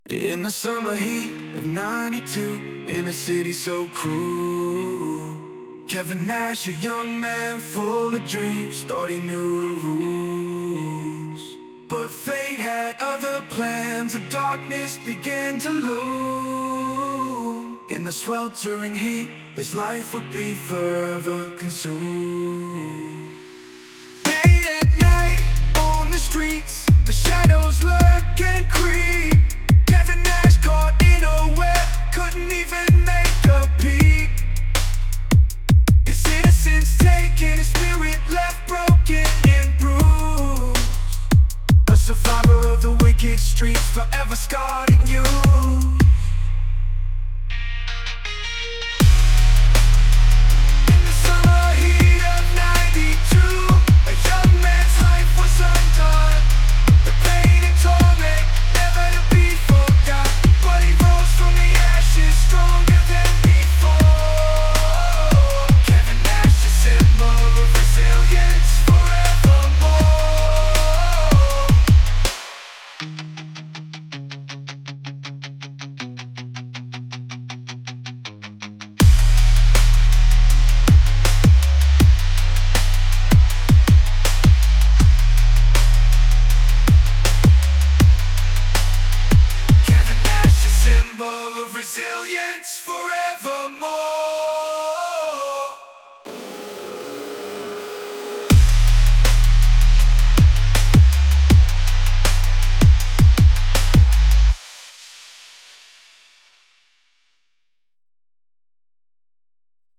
And you have been posting these shitty ai generated songs about Nash for days.